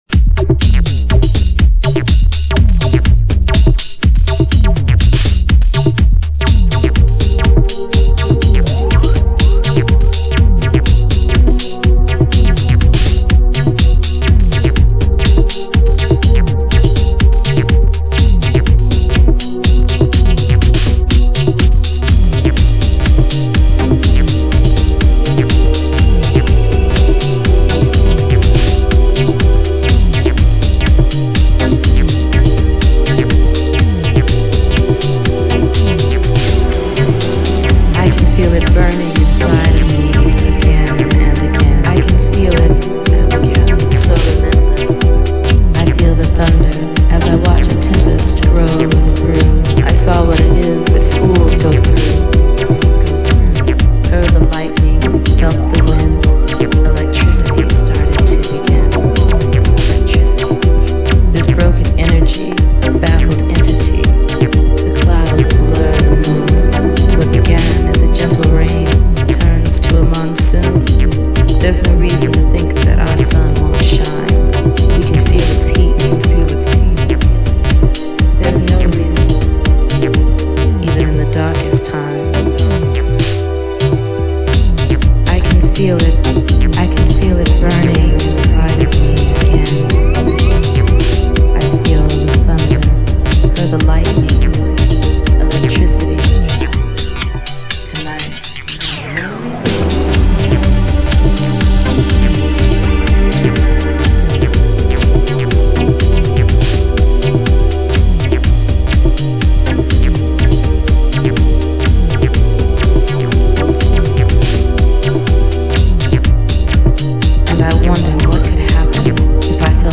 リアル・デトロイト・サウンド！！